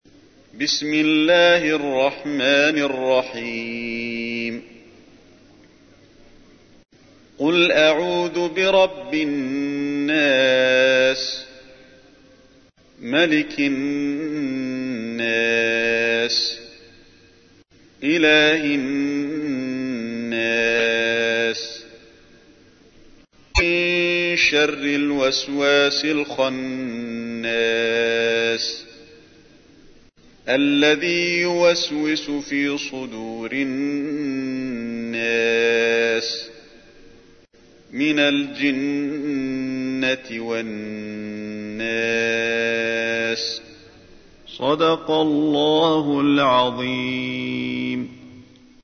تحميل : 114. سورة الناس / القارئ علي الحذيفي / القرآن الكريم / موقع يا حسين